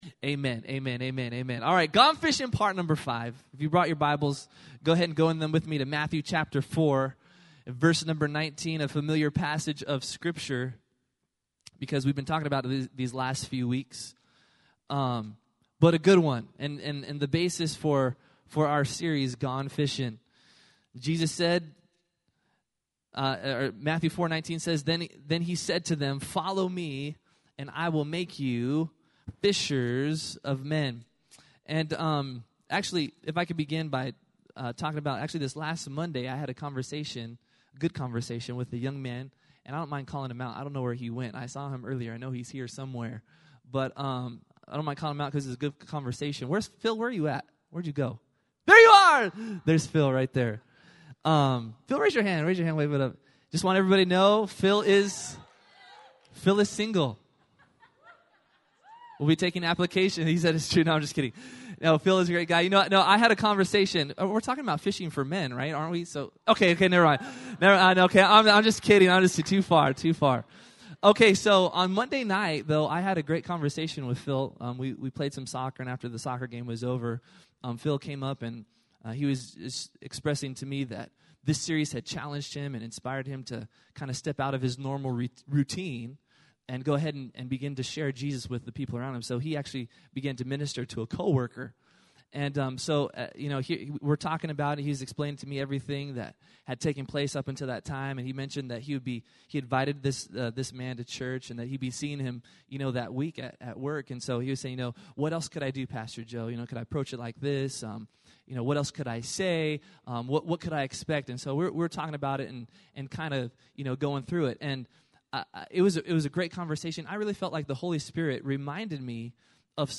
2015 at 7:00 pm Message Series Gone Fishin' Gone Fishin Part 1 Gone Fishin Part 2 Gone Fishin Part 3 Gone Fishin Part 4 Gone Fishin Part 5 WATCHING